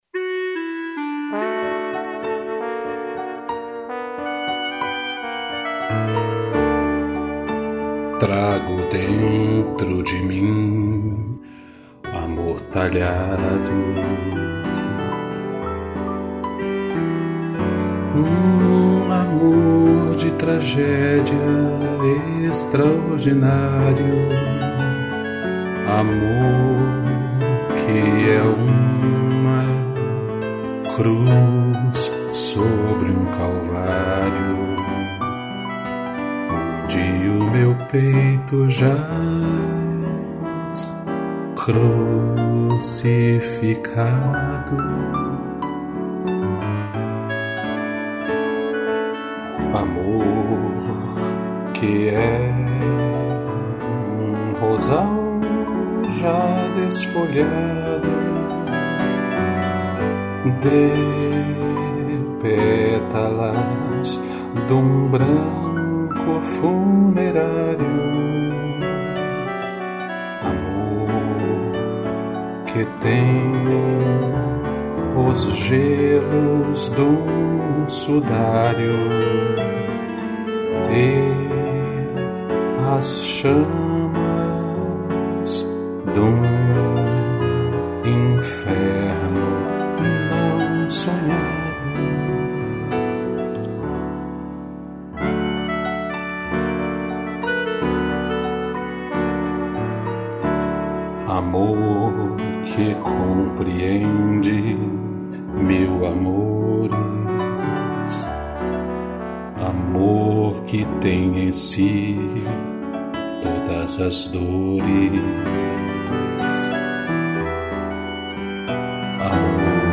Voz
2 pianos, clarineta e trombone